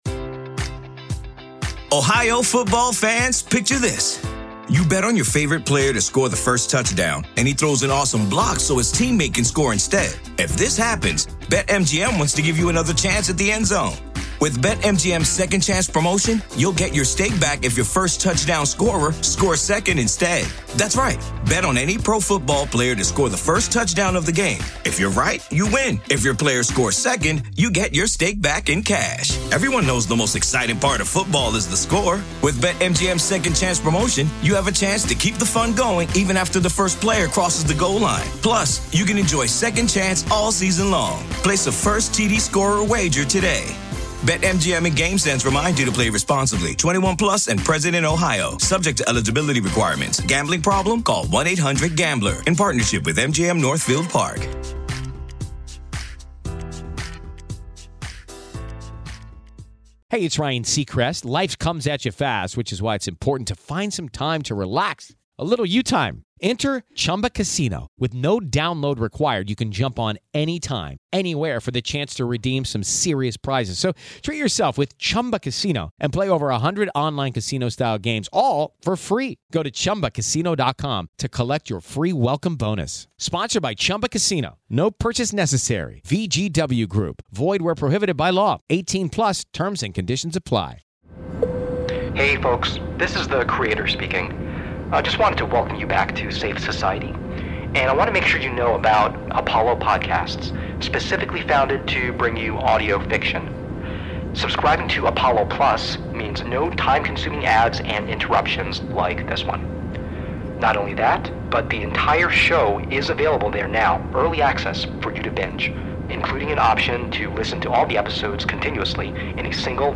CONTENT WARNINGS Mild language, allusion to body horror and disturbing creatures, moment of sudden shrill and unsetlling soun.